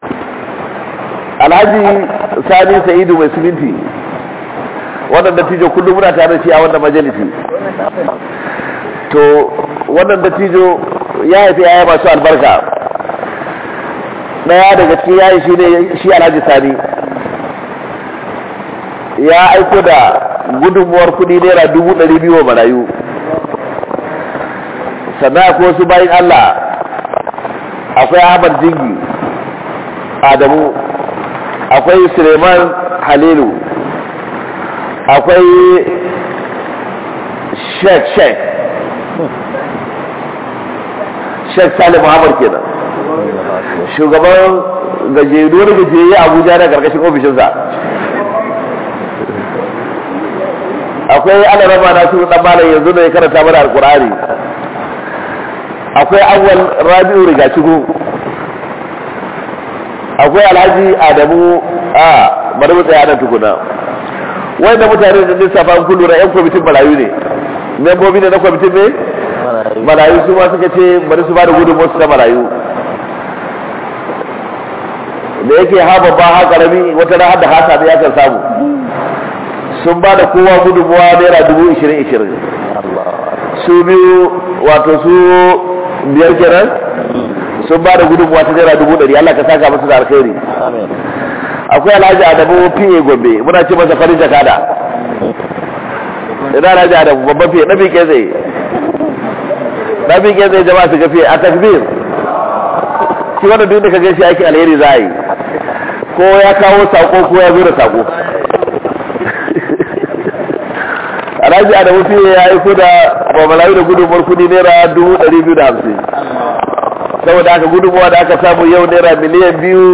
By Sheikh Kabiru Haruna Gombe Tafsir Duration: 47:19 3 downloads